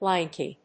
音節lank・y 発音記号・読み方
/lˈæŋki(米国英語), ˈlæŋki:(英国英語)/